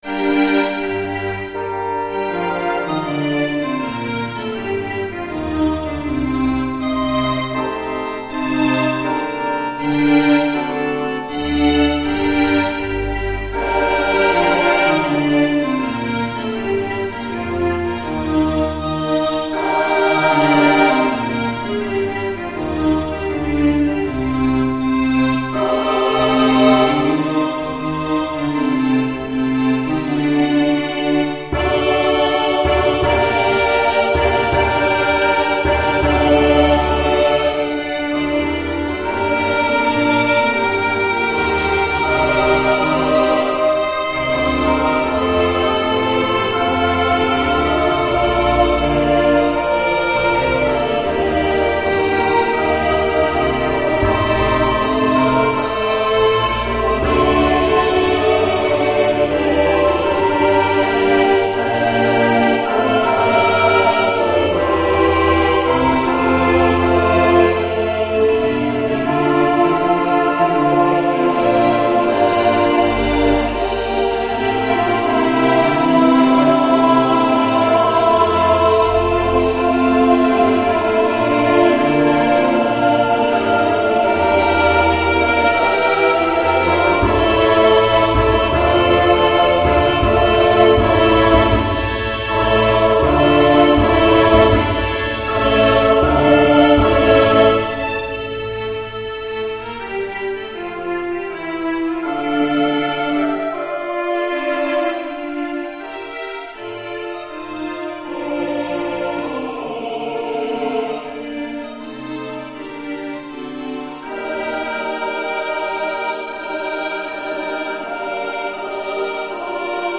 音取り音源
フルオーケストラ